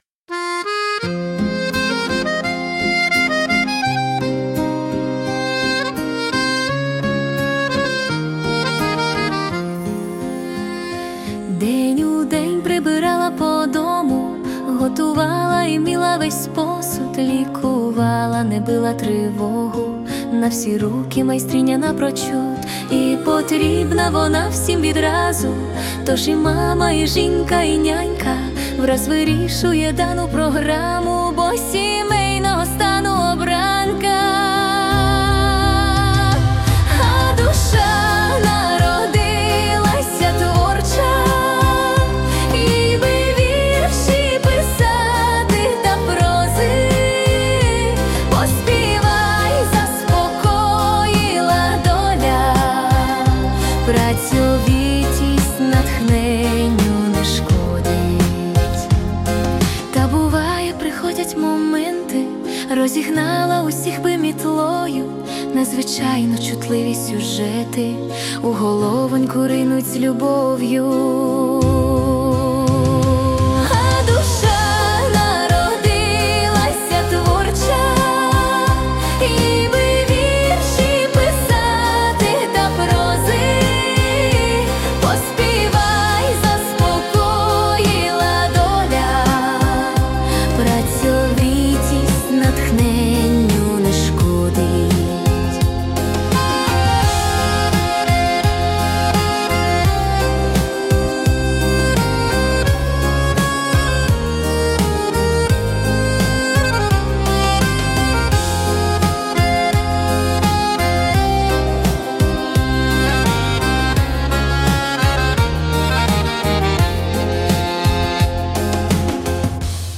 Музична композиція створена за допомогою SUNO AI
СТИЛЬОВІ ЖАНРИ: Ліричний
ОЙ, ЯКА ГОЛОСИСТА ПІСНЯ! smile 12 sp 021 give_rose hi